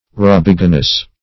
Search Result for " rubiginous" : The Collaborative International Dictionary of English v.0.48: Rubiginose \Ru*big"i*nose`\, Rubiginous \Ru*big"i*nous\, a. [L. rubiginosus, fr. rubigo, robigo, rust: cf. F. rubigineux.]